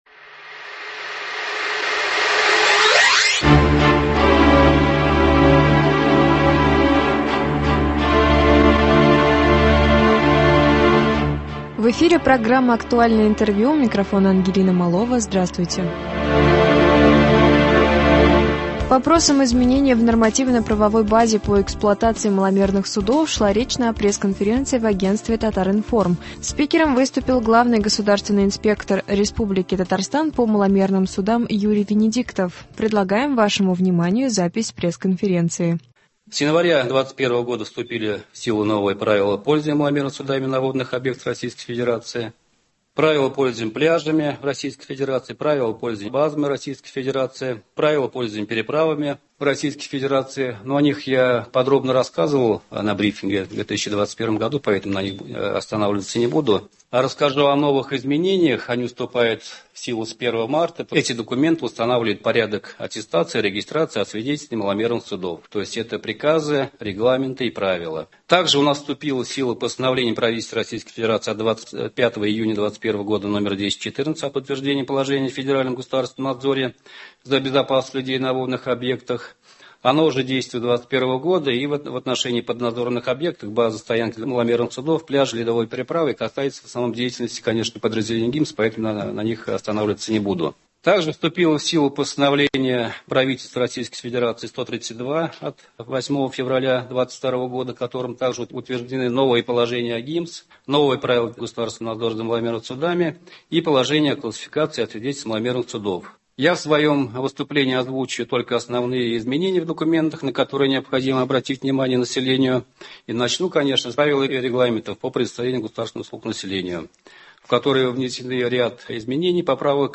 Актуальное интервью (02.03.22)